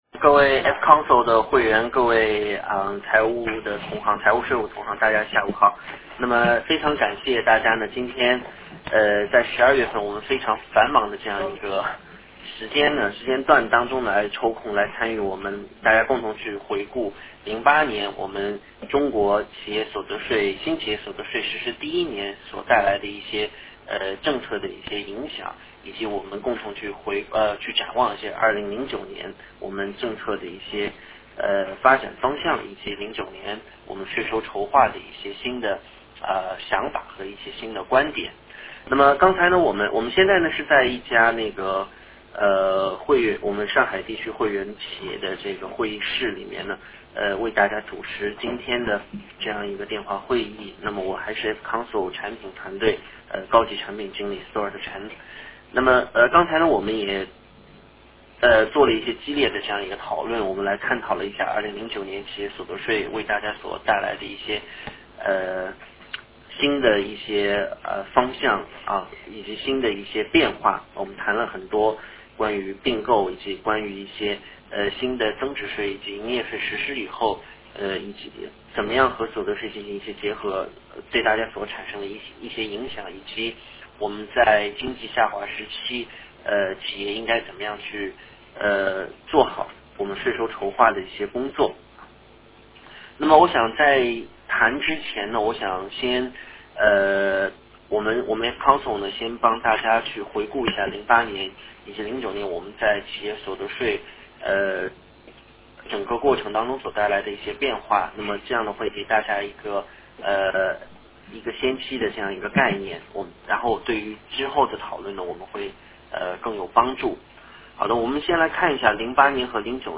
活动形式：线下畅想+电话会议